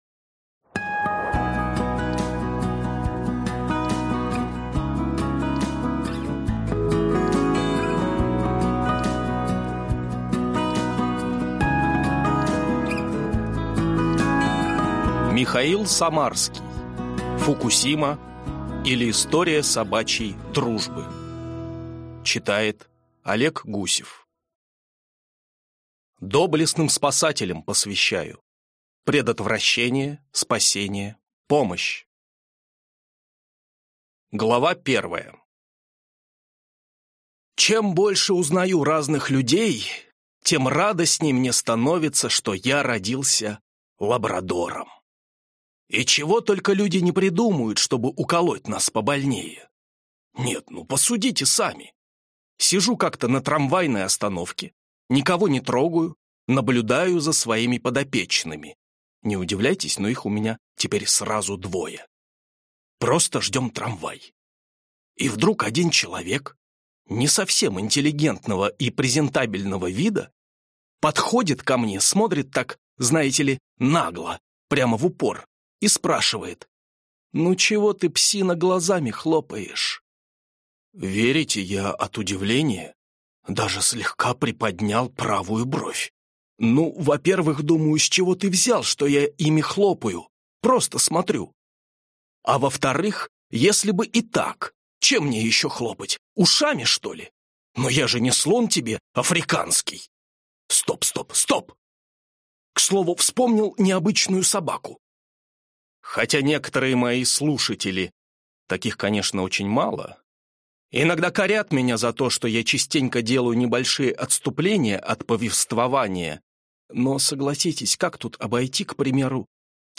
Аудиокнига Фукусима, или История собачьей дружбы | Библиотека аудиокниг